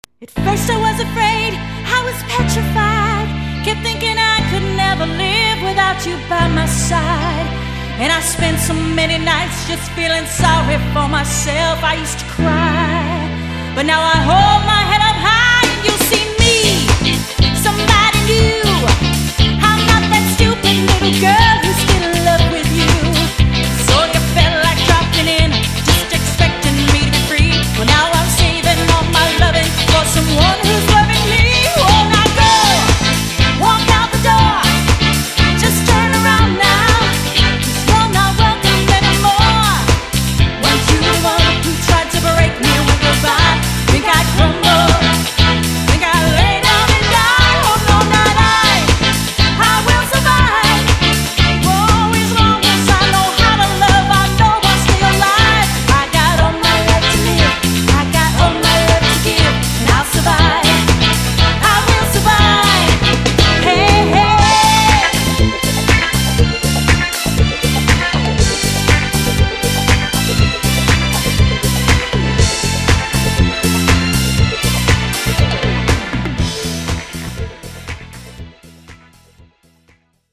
My drum parts
Dance/R&B/Disco